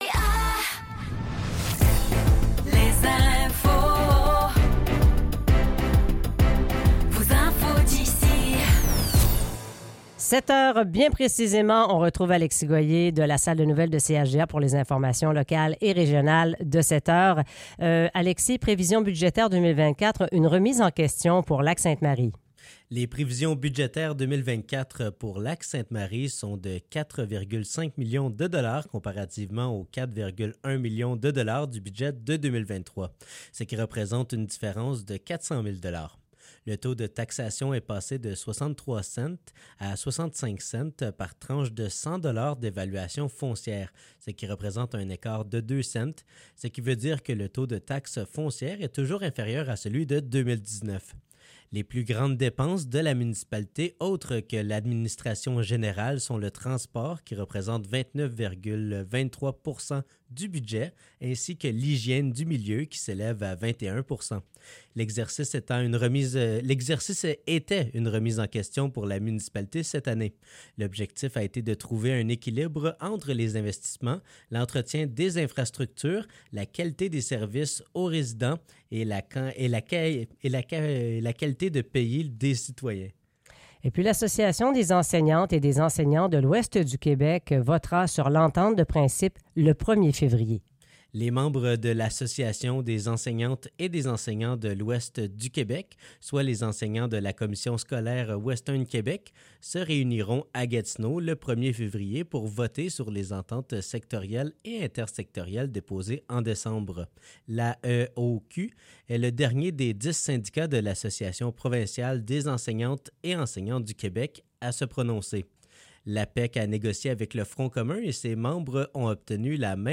Nouvelles locales - 31 janvier 2024 - 7 h